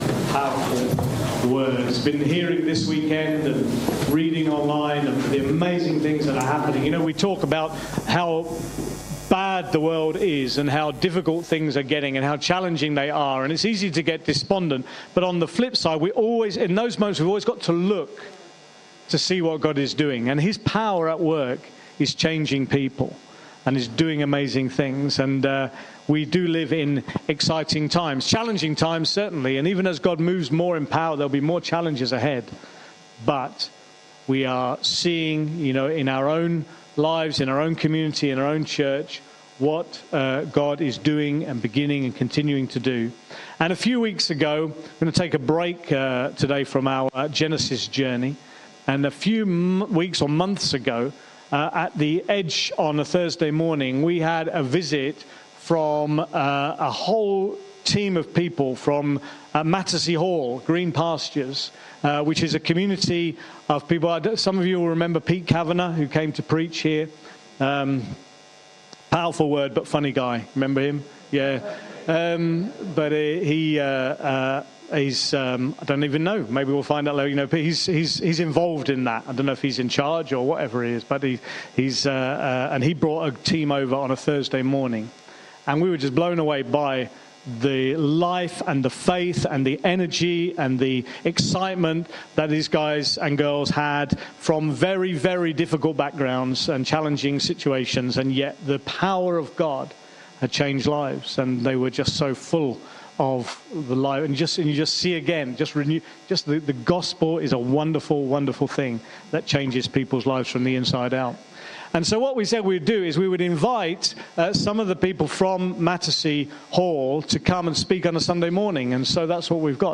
Sunday Gathering – Green Pastures – Rebuilding Together: Finding Hope and Purpose in Broken Places